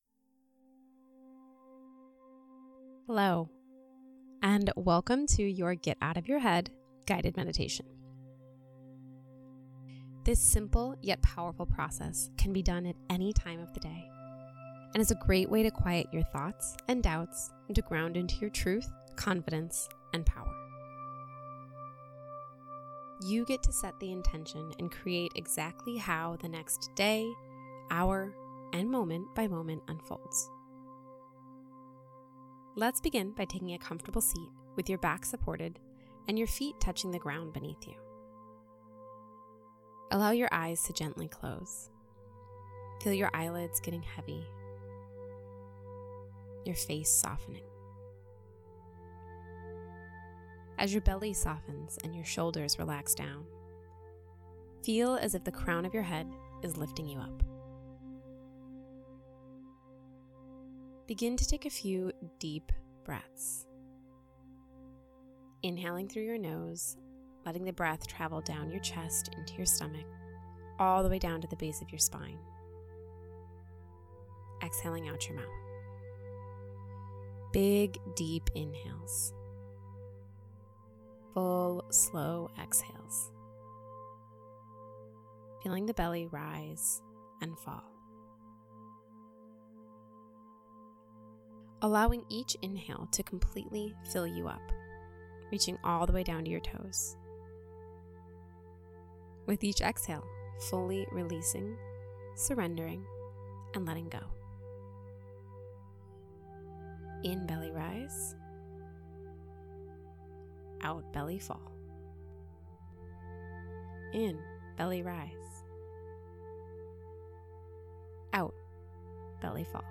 RELEASING FEARS AND ANXIETY GUIDED MEDITATION
GetOutOfYourHead_GuidedMeditation.mp3